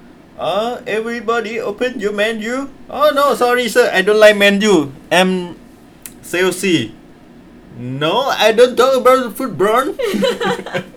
S1 = Brunei female S2 = Laos male Context: S2 is talking about his experiences in a restaurant in India. He is imitating an Indian accent, which might contribute to the difficulty in understanding the final word.
Then, when football is pronounced with [n] rather than [l] at the end, she cannot understand it. S1 laughs in response as she recognises the use by S2 of an Indian accent, even though she could not actually understand the joke.